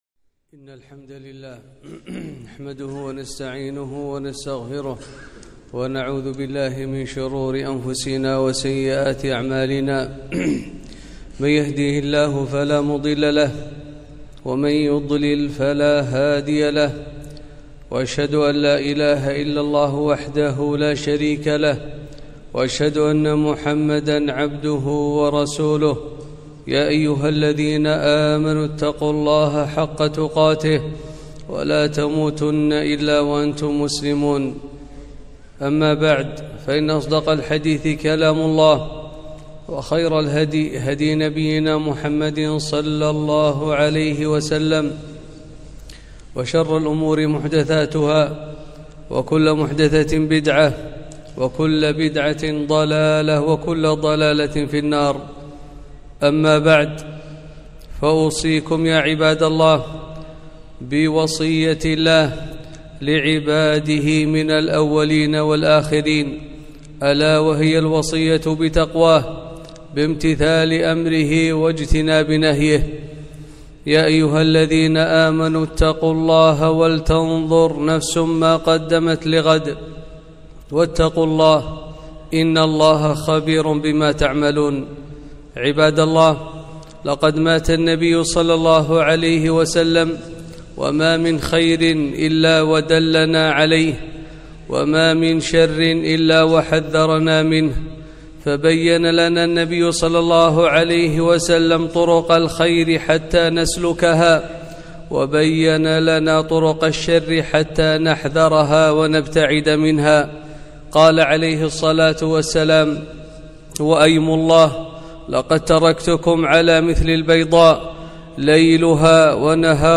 خطبة - أثر الذنوب والمعاصي على البلاد والعباد